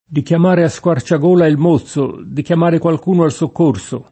mozzo [m1ZZo] s. m. («garzone; ragazzo di bordo») — es. con acc. scr.: di chiamare a squarciagola il mózzo, di chiamare qualcuno al soccorso [
di kLam#re a SkUar©ag1la il m1ZZo, di kLam#re kUalk2no al Sokk1rSo] (D’Annunzio)